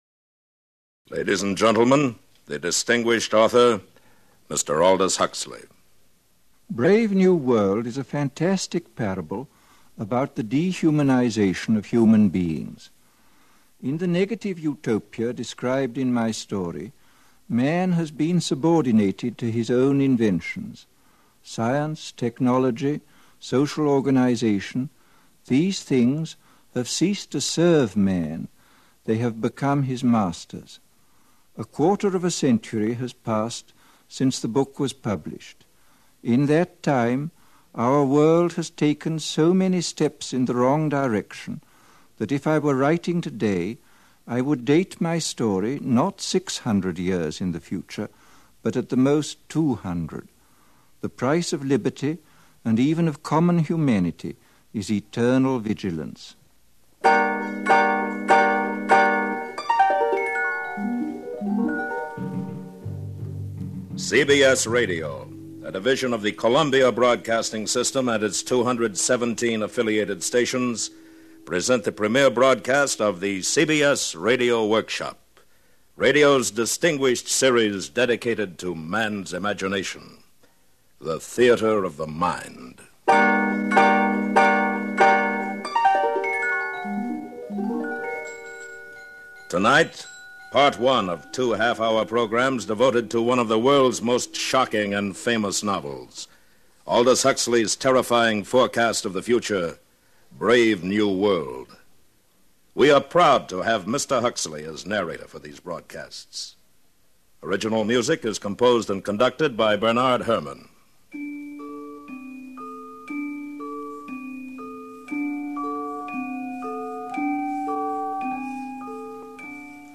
CBS Radio Workshop with host and narrator William Conrad